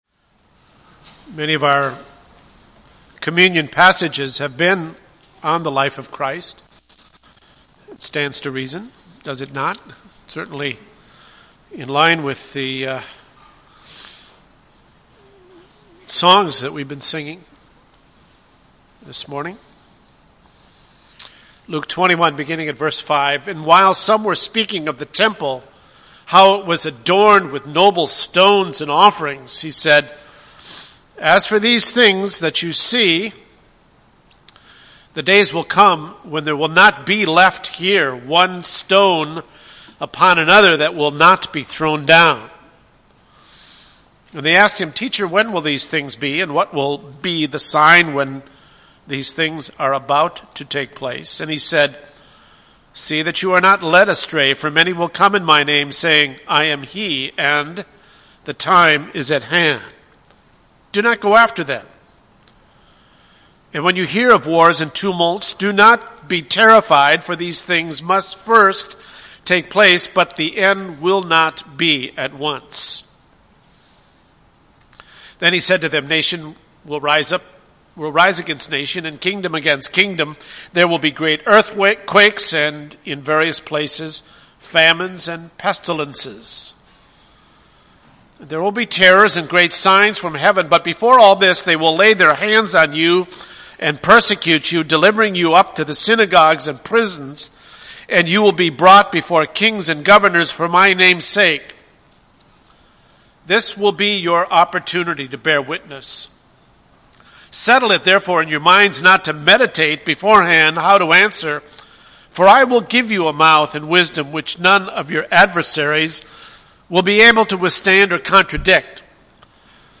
Communion Homily: The True and Everlasting Temple
Service Type: Sunday Morning